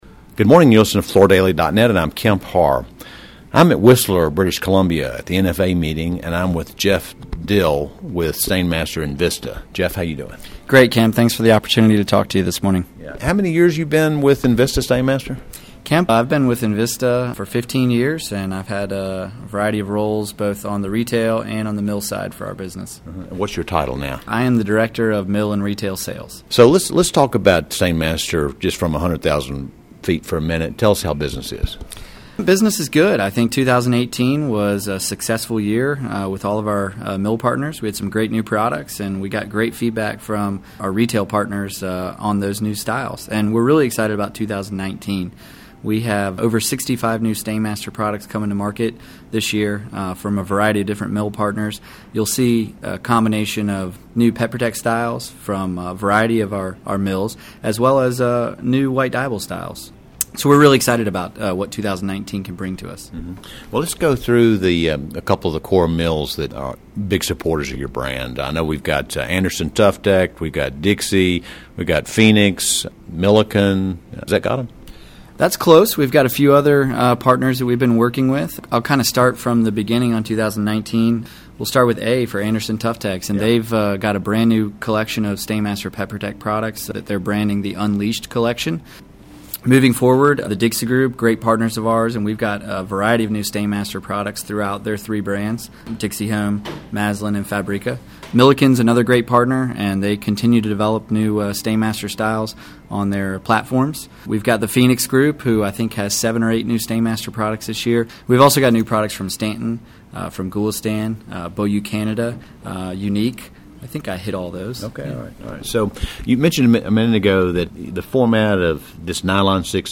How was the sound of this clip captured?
Listen to the interview, recorded at the National Floorcovering Alliance meeting in Whistler, British Columbia, for more details.